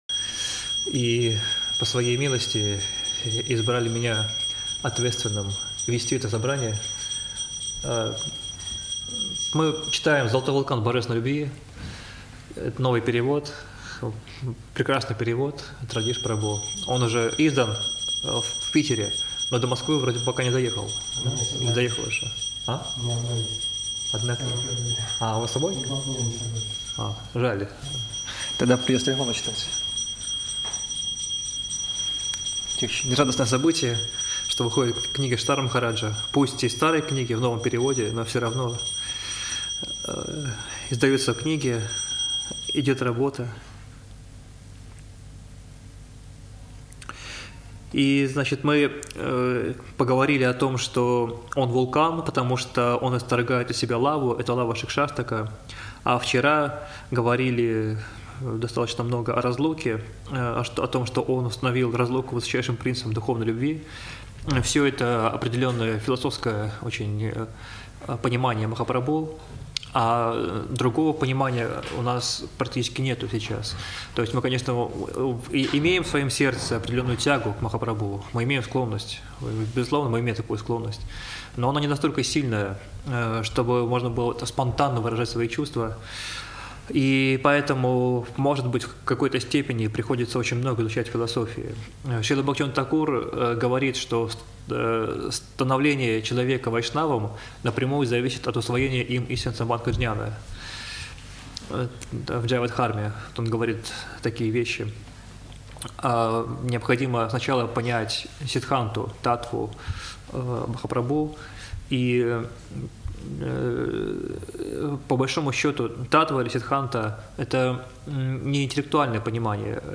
Москва, Кисельный